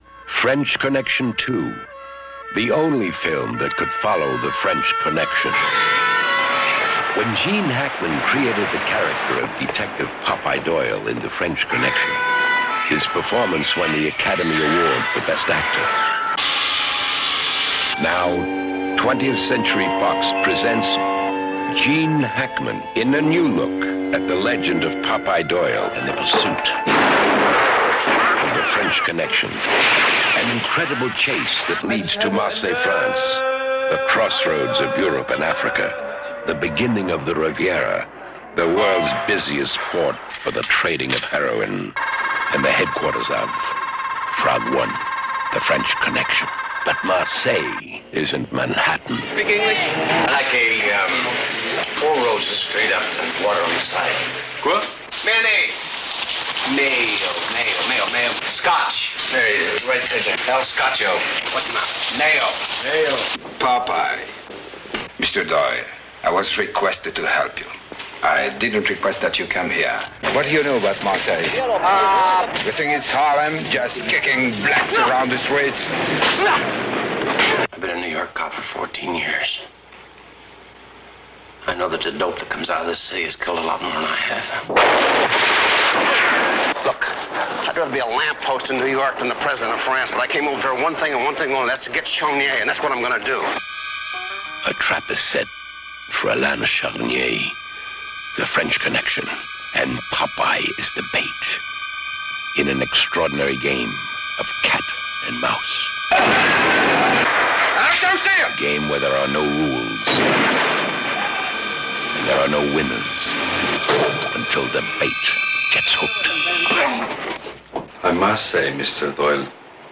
Friday Night Watch Party Trailer II (1975)